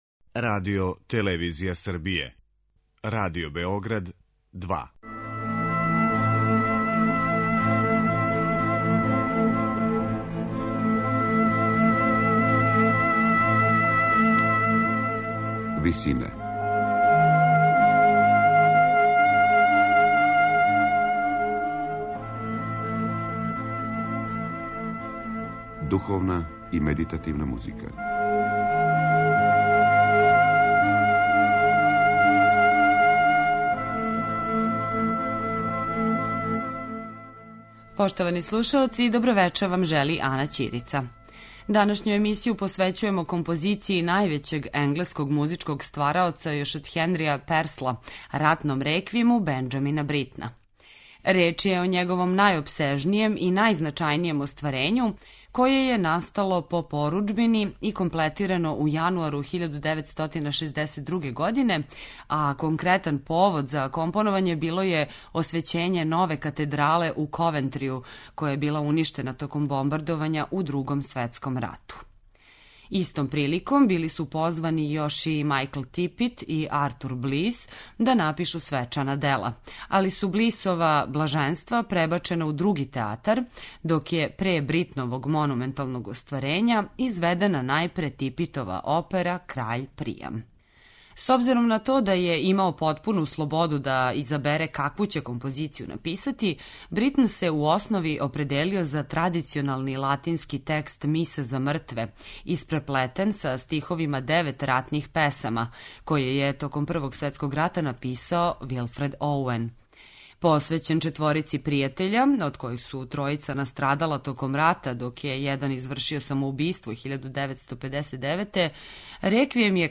Пијаниста, диригент и један од најзначајнијих енглеских композитора XX века своје најзначајније и најобимније вокално-инструментално дело писао је током 1961, а довршио јануара следеће године.